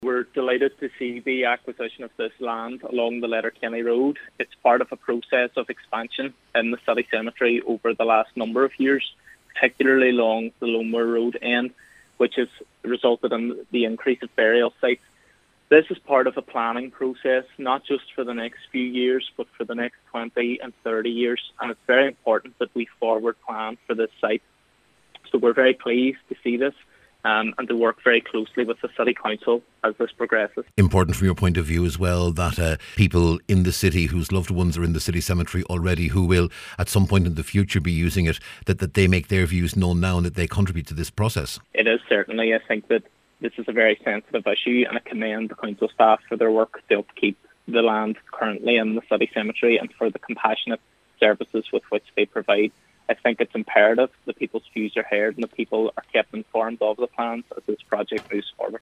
With that in mind, he’s urging the council to consult widely, and calling on people in the area to make their views known: